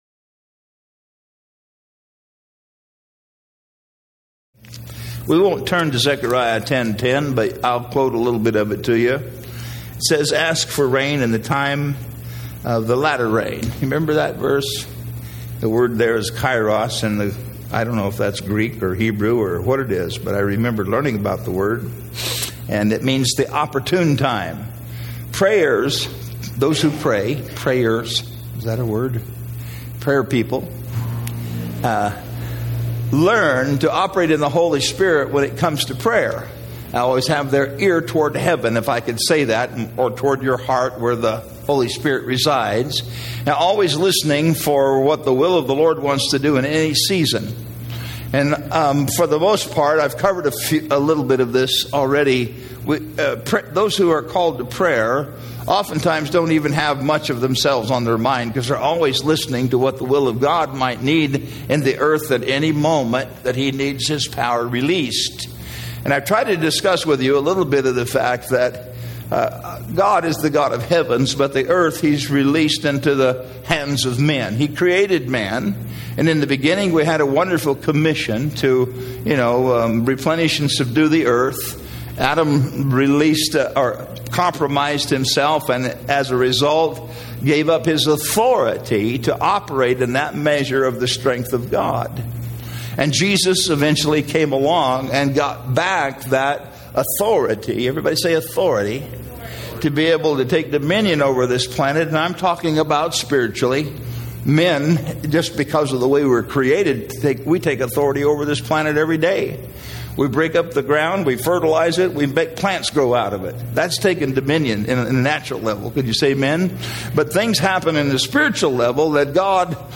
Oct 19, 2016 The Importance of Living a Consecrated Life By: Greg Laurie Series: A New Beginning - Harvest Ministries Greg Laurie-Sunday, October 15-10am Service Evangelical Counsels 915.